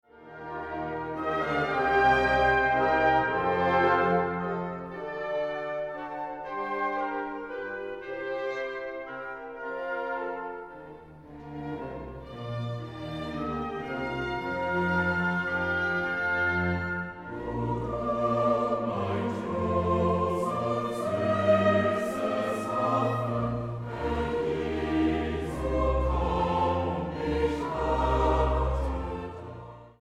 Chor, Bläser